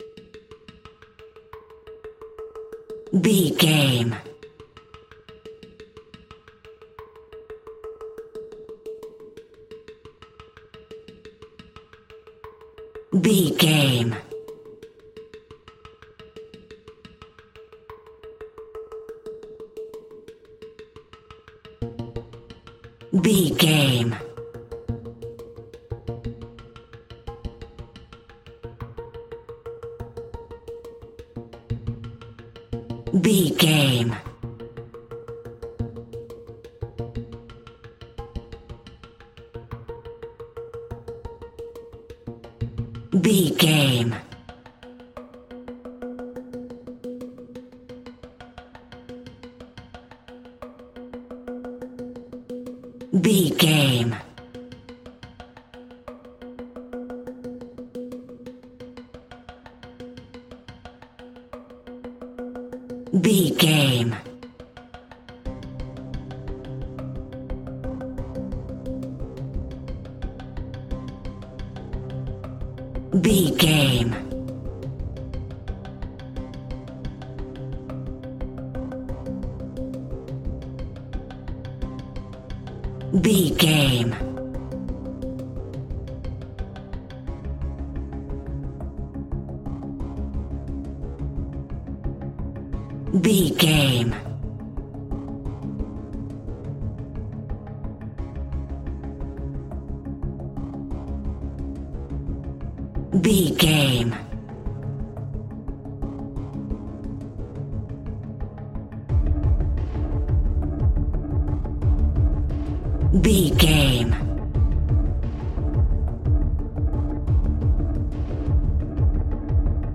Tension Percussion.
In-crescendo
Atonal
scary
ominous
eerie
strings
percussion
suspenseful